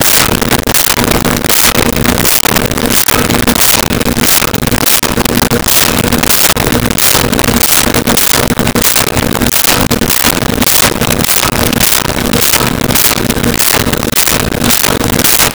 E Typewriter Type Slow
E-Typewriter Type Slow.wav